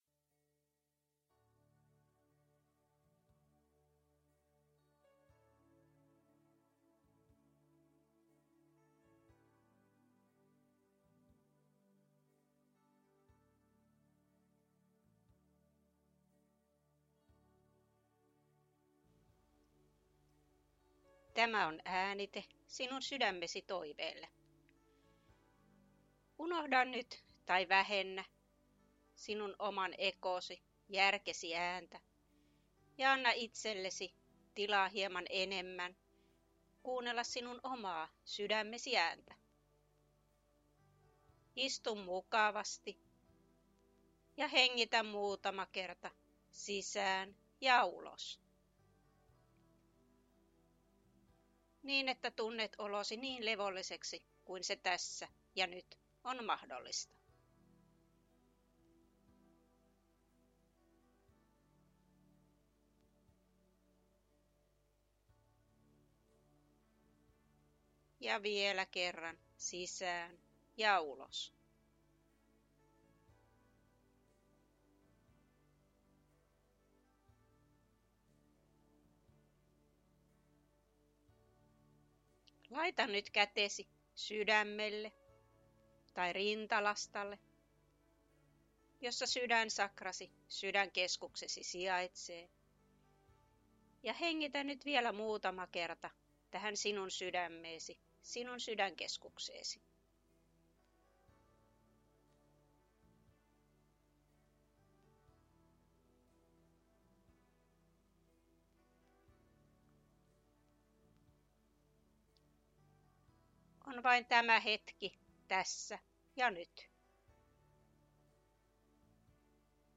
Sydämesi-toive-musiikilla.mp3